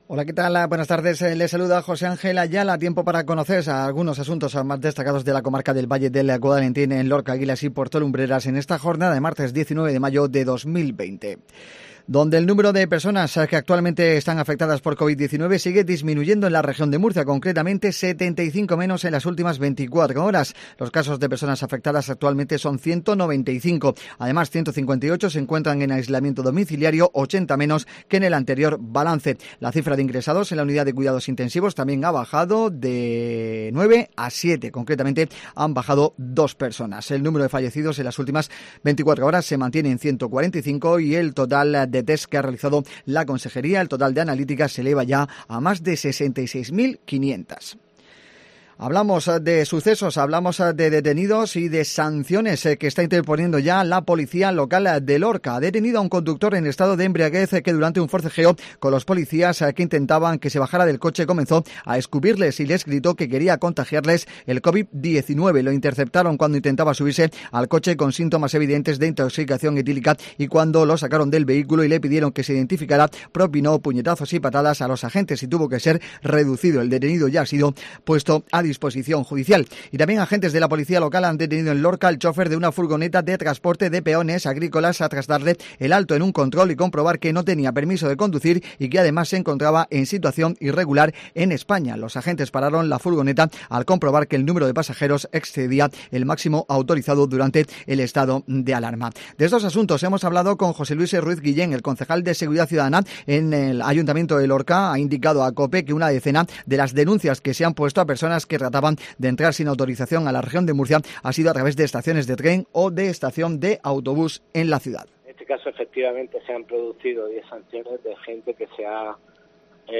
INFORMATIVO MEDIODIA COPE